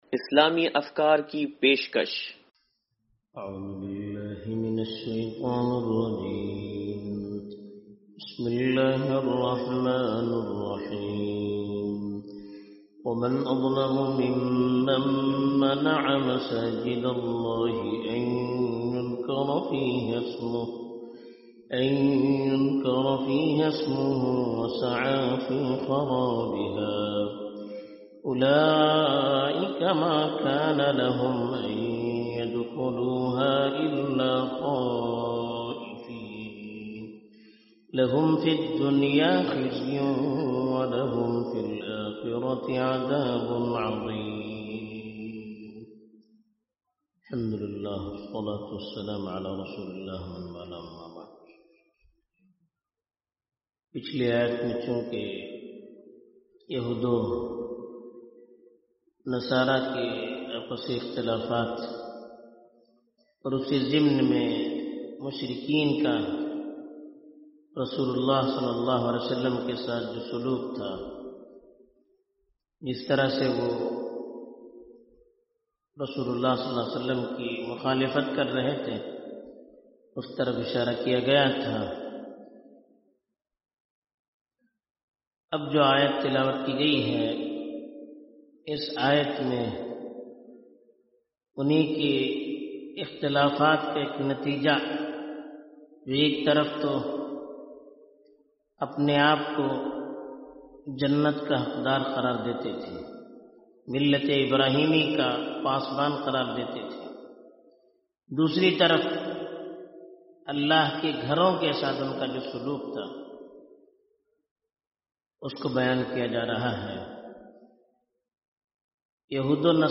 درس قرآن نمبر 0080